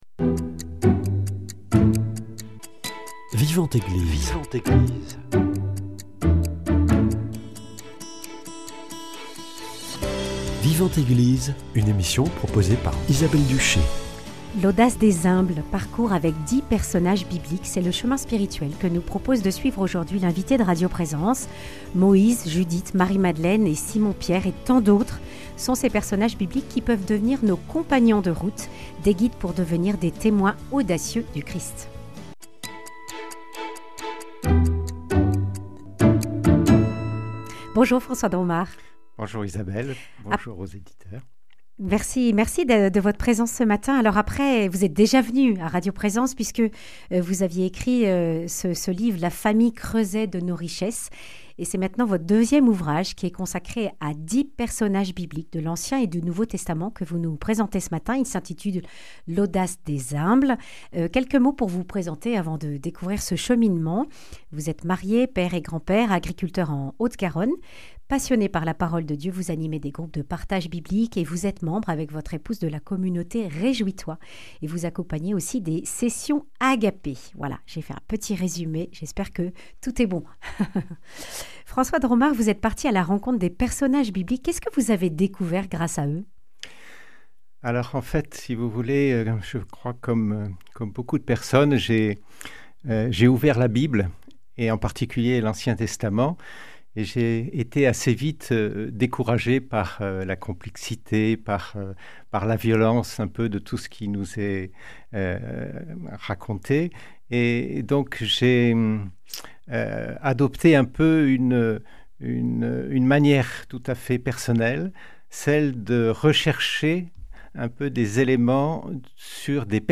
Vivante Eglise